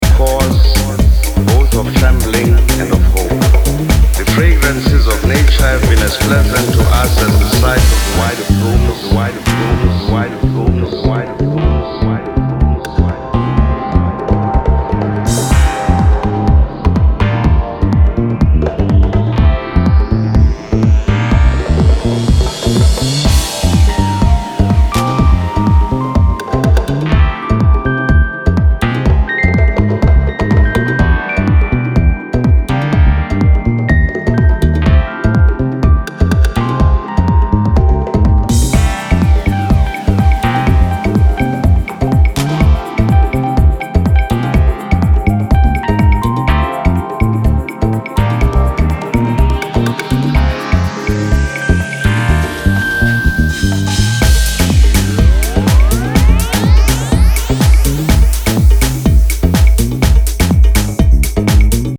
• Afro House